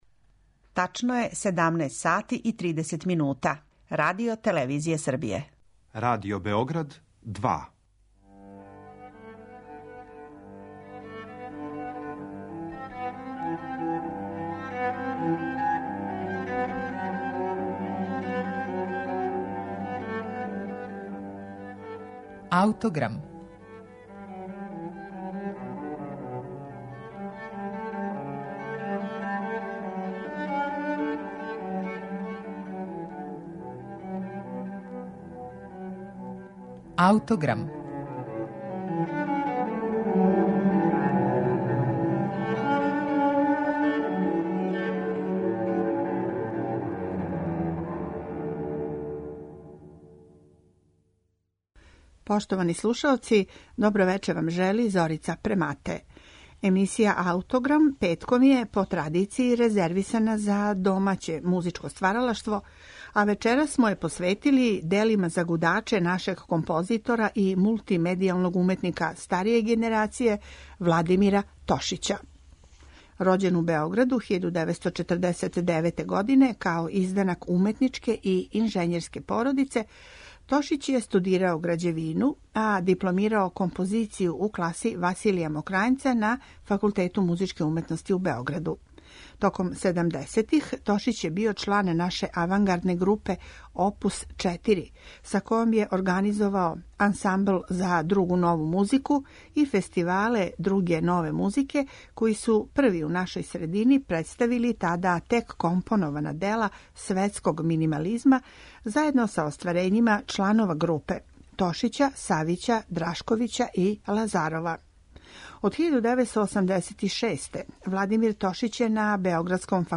дела за гудаче